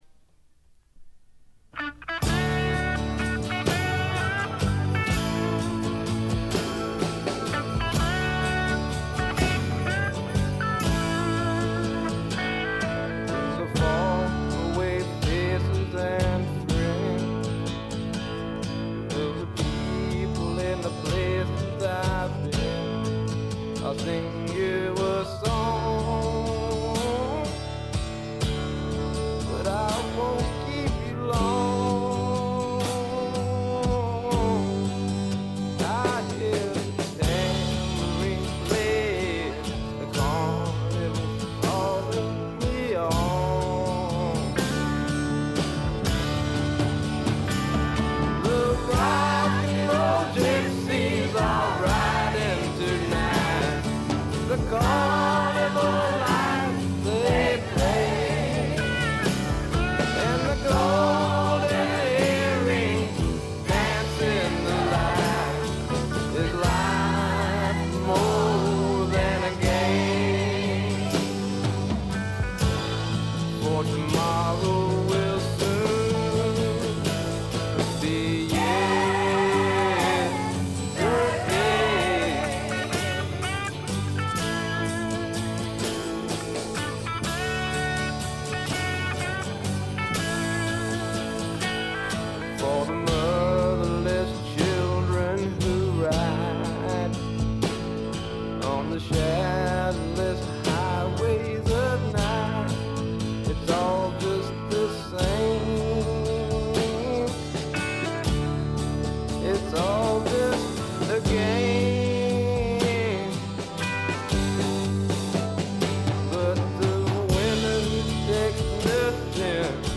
ノイズ感無し。
まさしくスワンプロックの真骨頂。
試聴曲は現品からの取り込み音源です。